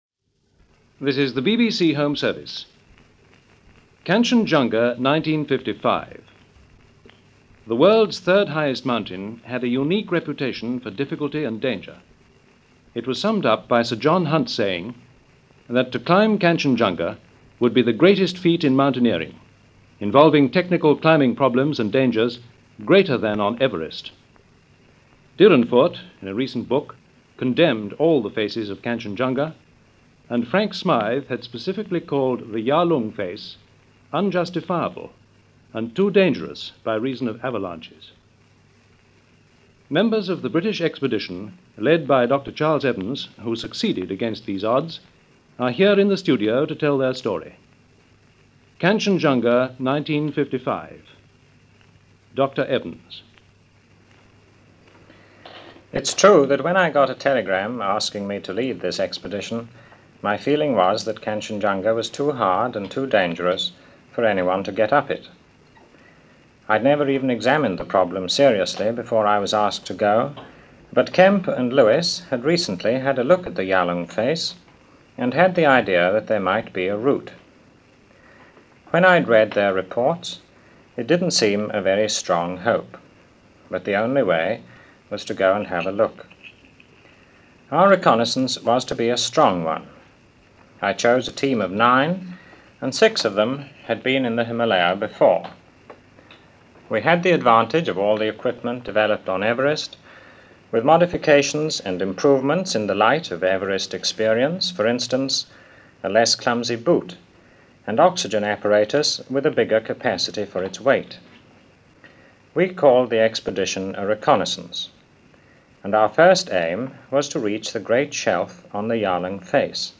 Climbing Kangchenjunga 1955 - Past Daily After Hours Reference Room - June 18, 1955 - BBC World Service Documentary.